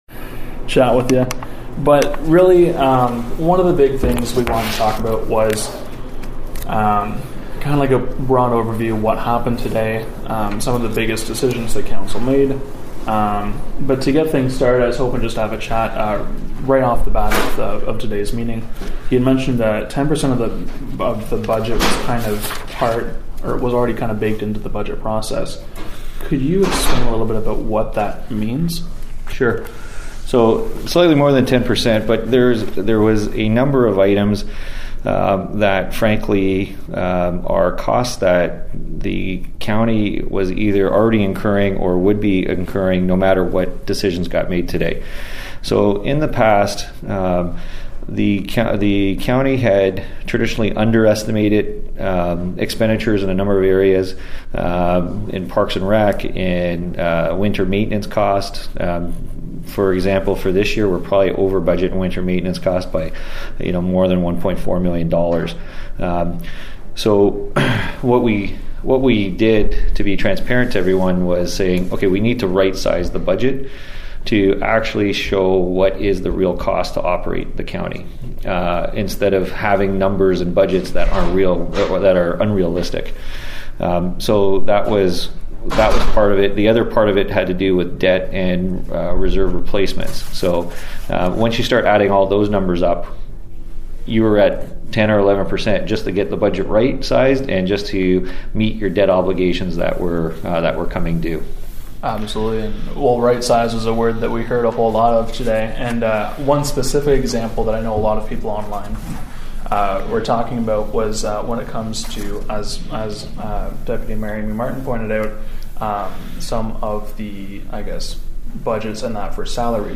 myFM’s Day 1 Budget wrap-up includes exclusive interview